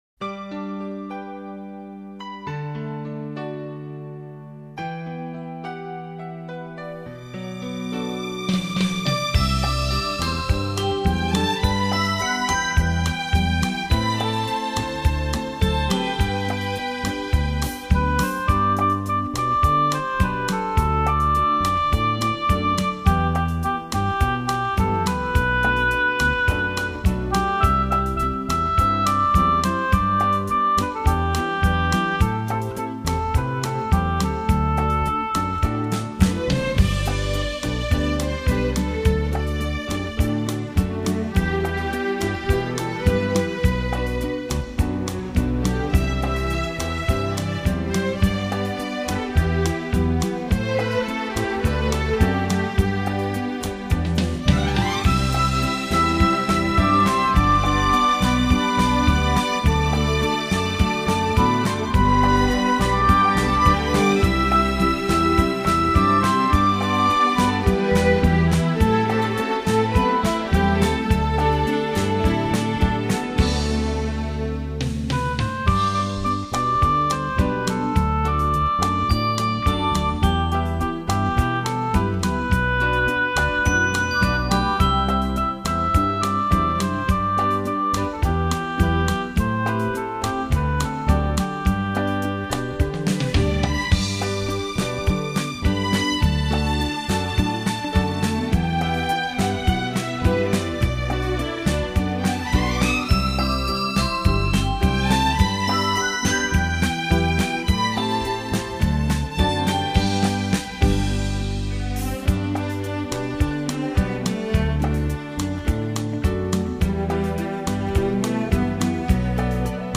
流行音乐交谊舞曲